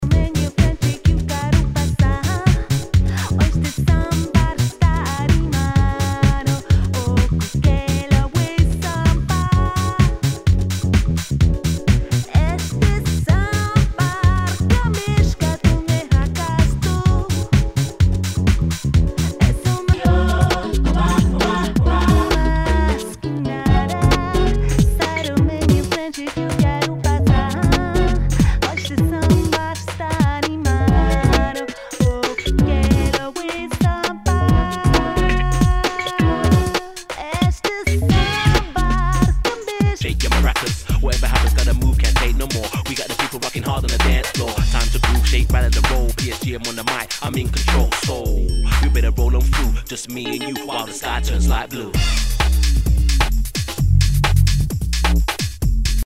HOUSE/TECHNO/ELECTRO
ラテン・ハウス / UK Garage！
[VG ] 平均的中古盤。スレ、キズ少々あり（ストレスに感じない程度のノイズが入ることも有り）